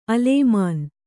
♪ alēmān